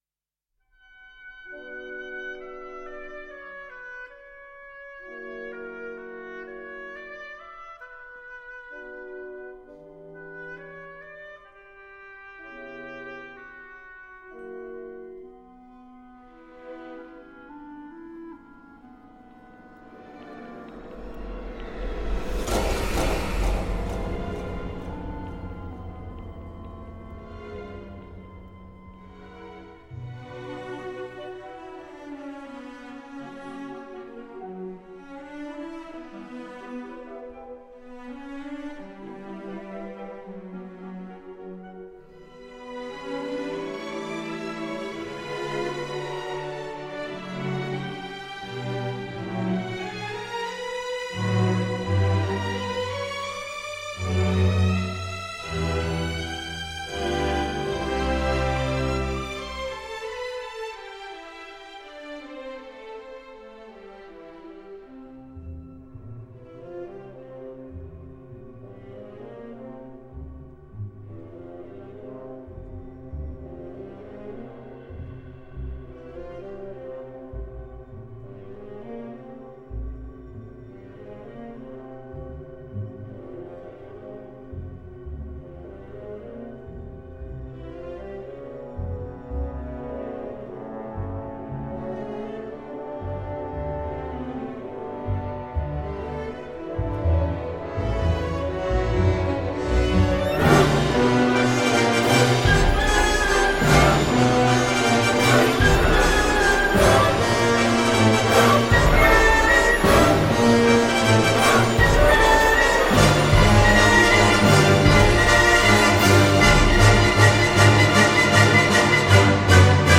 Soundtrack, Drama